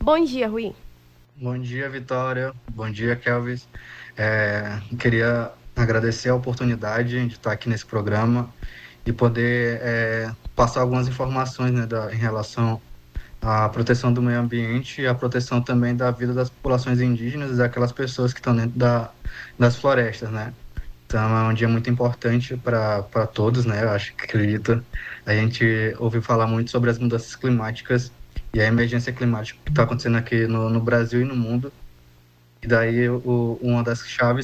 Nome do Artista - CENSURA - ENTREVISTA (DIA MUNDIAL PROTECAO FLORESTAS) 17-07-23.mp3